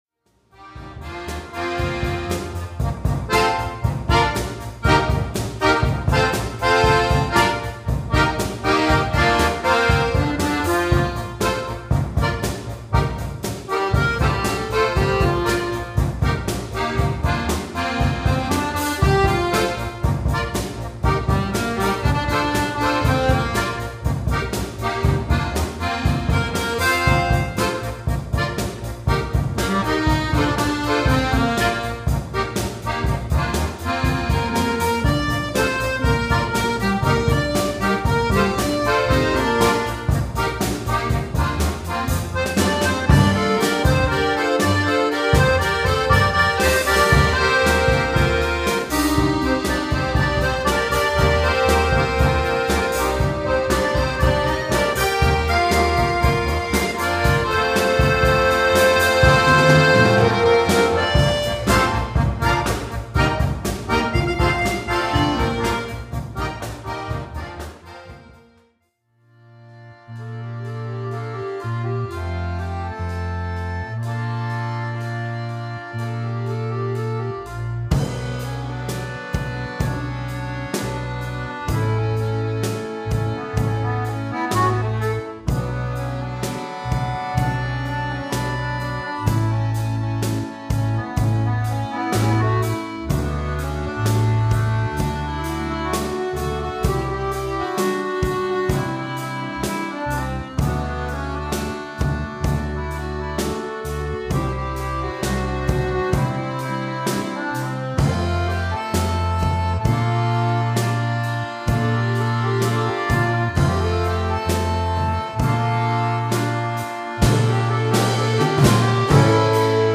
Recueil pour Accordéon - Orchestre d'Accordéons